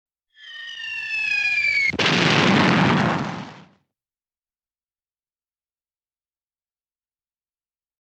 На этой странице собраны исторические записи звуков залпов «Катюши» — легендарной реактивной системы, ставшей символом победы в Великой Отечественной войне.
Звук свиста падающего снаряда, взрыв, залп Катюши